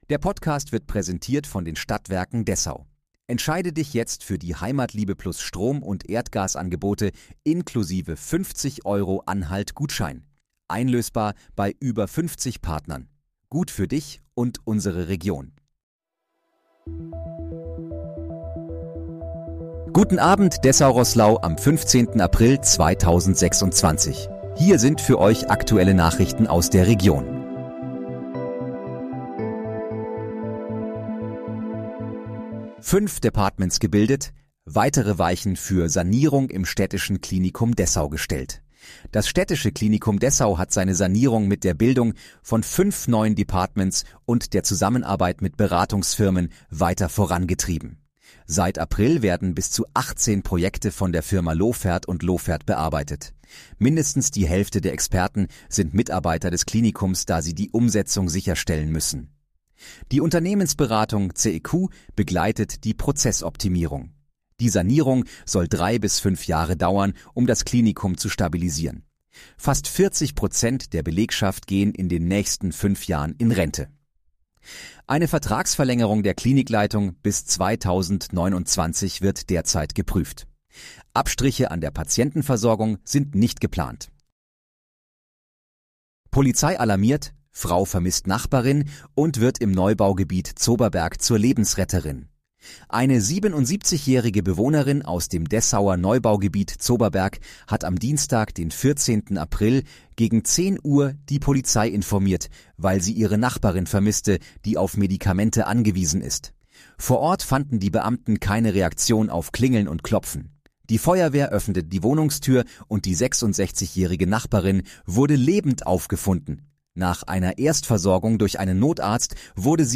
Guten Abend, Dessau-Roßlau: Aktuelle Nachrichten vom 15.04.2026, erstellt mit KI-Unterstützung